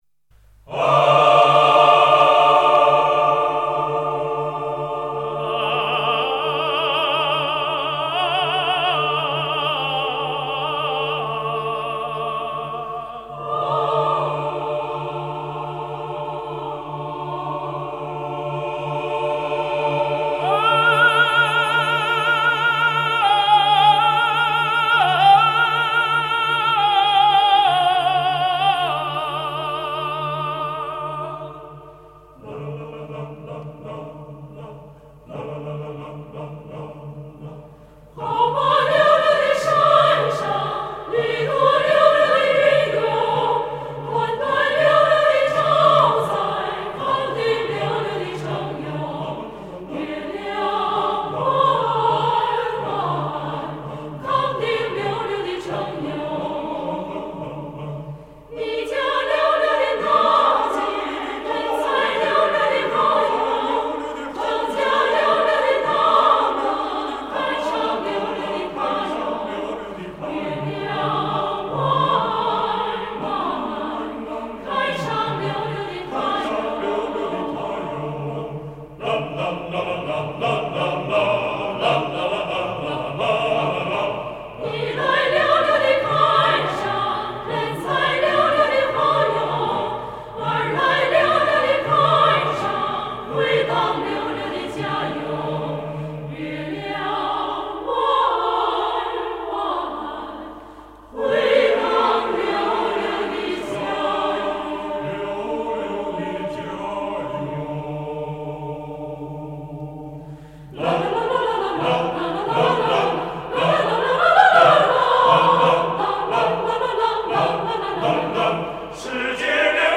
后期母带处理：MANLEY参数式真空管EQ及MILLENNIA NSSQ-2Q胆EQ
声场处理器：Lexicon-480L参数式处理器 CDR:HHB-580